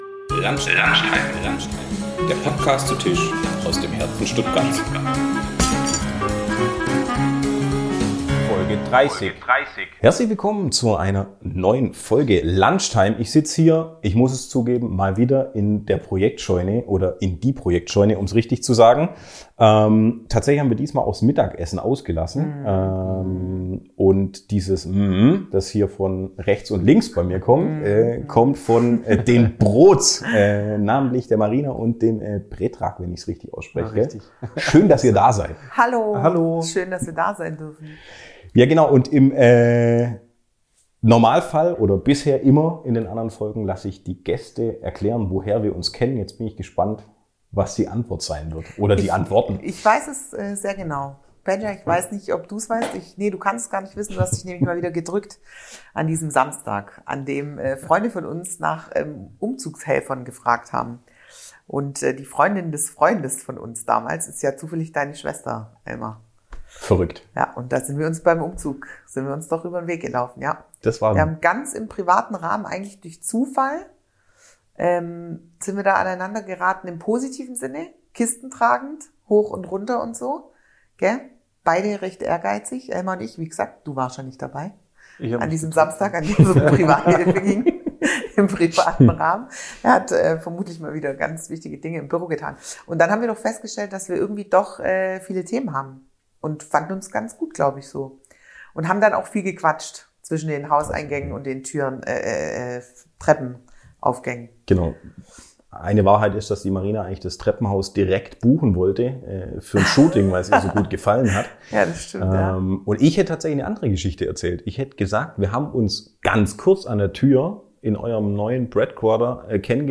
In dieser Folge haben wir kurzerhand den Lunch aufgrund von einem knappen Zeitfenster weggelassen und zu dritt in der Sitzecke eingecheckt. Und waren irgendwie schon vor dem Drücken von Record wieder mittendrin im wilden Mix aus Themen und Stories.